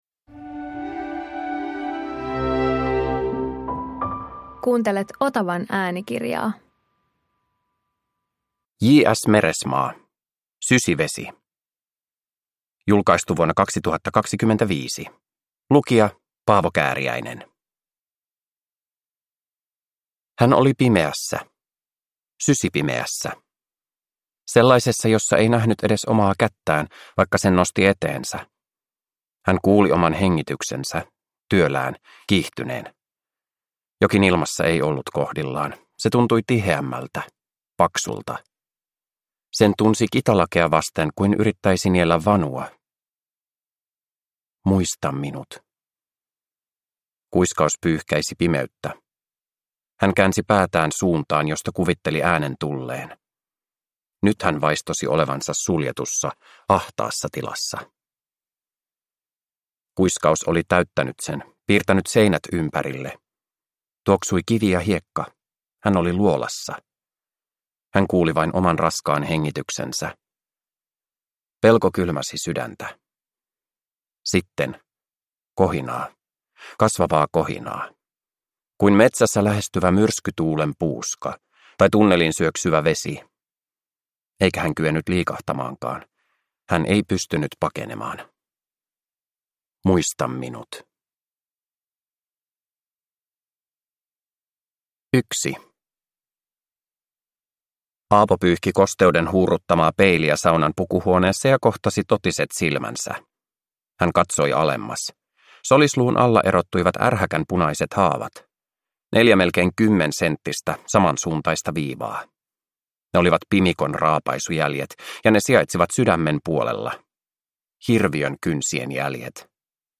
Sysivesi – Ljudbok